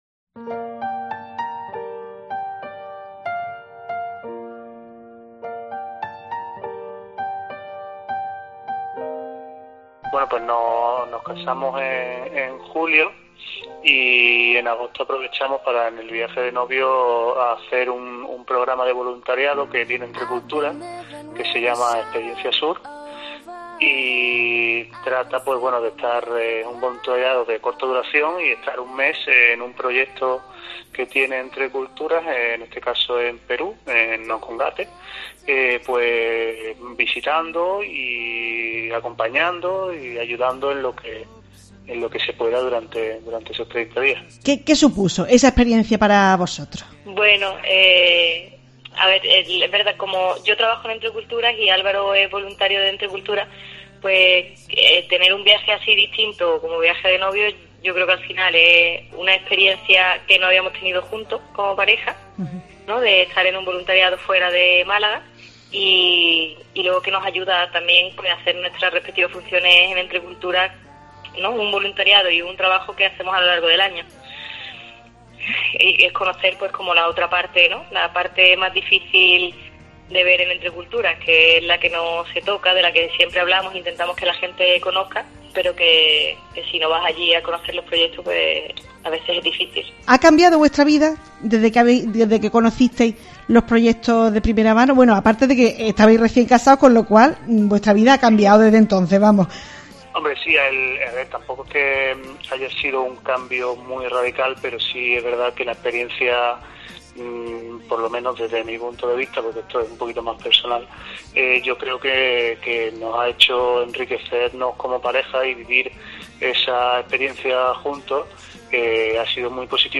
Entrevista en Iglesia en Málaga